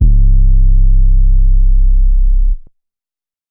808_Oneshot_Drowning_C
808_Oneshot_Drowning_C.wav